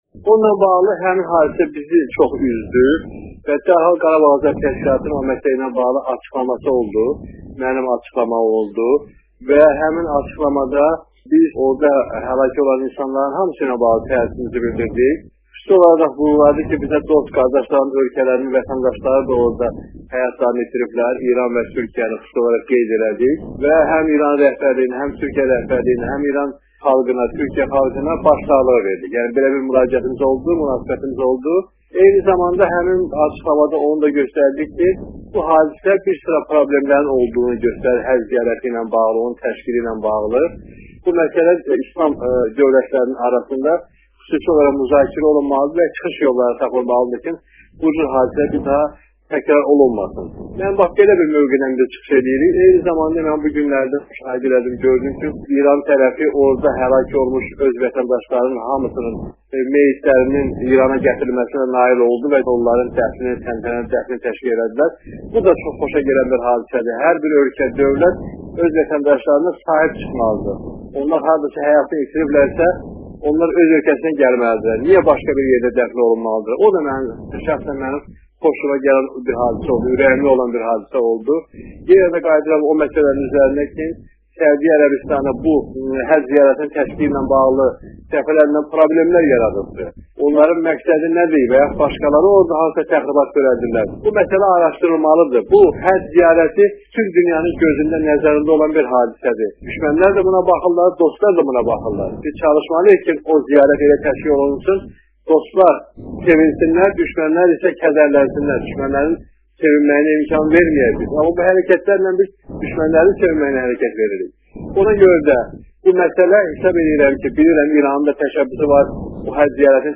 azəri radiosuna eksklüziv müsahibə verərkən deyib